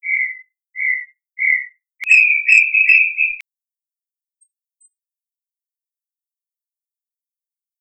Crypturellus obsoletus obsoletus - Perdíz de monte
perdizdemonte.wav